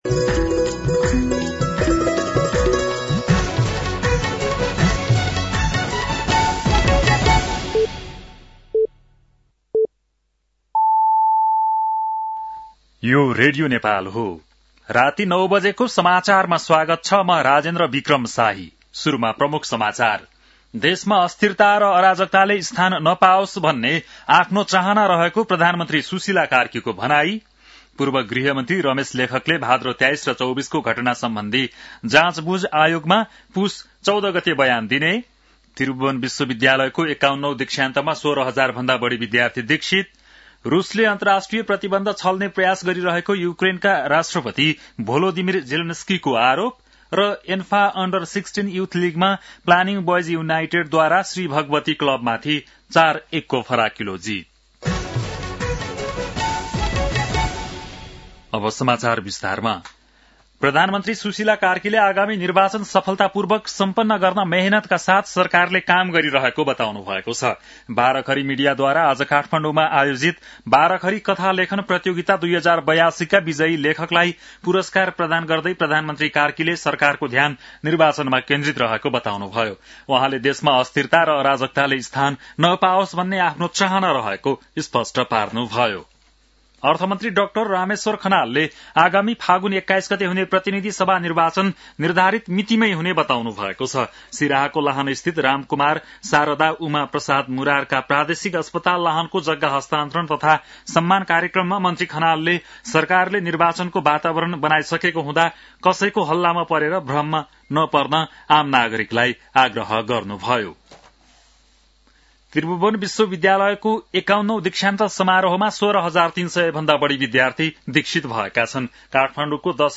बेलुकी ९ बजेको नेपाली समाचार : १० पुष , २०८२
9-PM-Nepali-NEWS-1-1.mp3